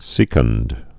(sēkŭnd, sĭ-kŭnd)